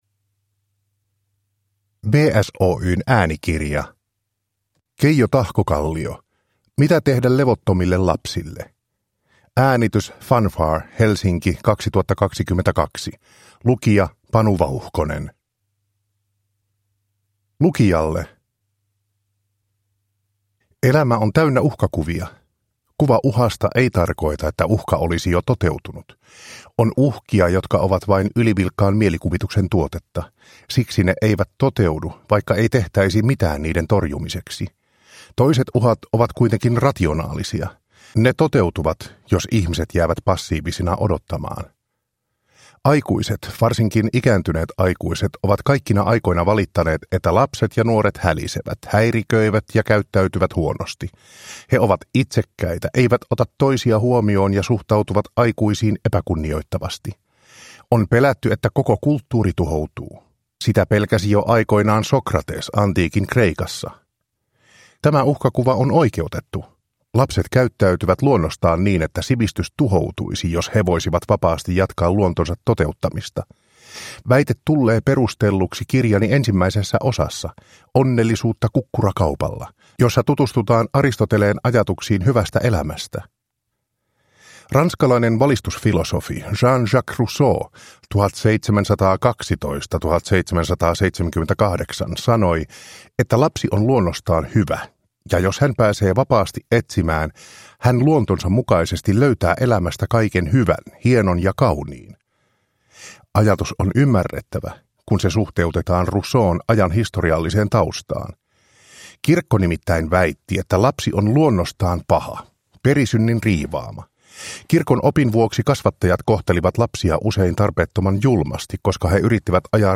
Mitä tehdä levottomille lapsille – Ljudbok – Laddas ner